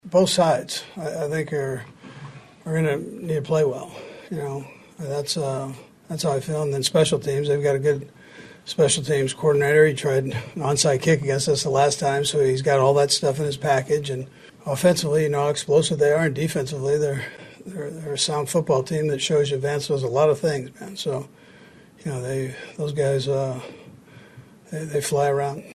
Chiefs Coach Andy Reid says it will take a full team effort to win the game.